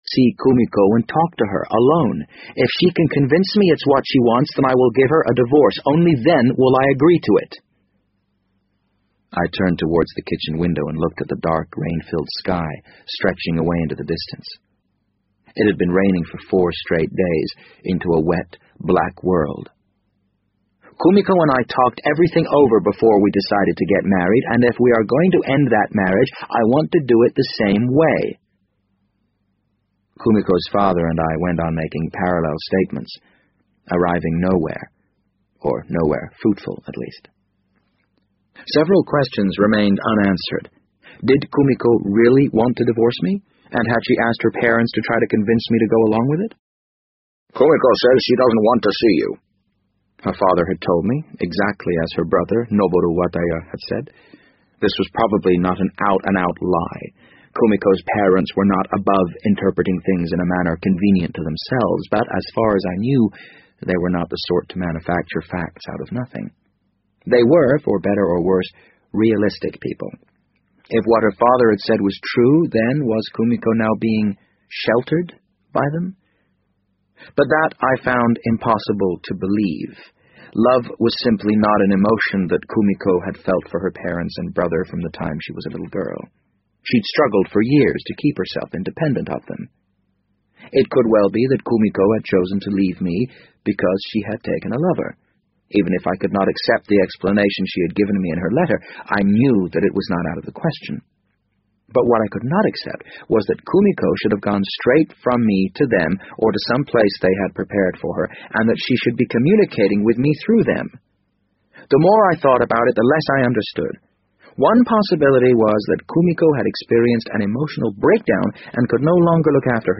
BBC英文广播剧在线听 The Wind Up Bird 009 - 6 听力文件下载—在线英语听力室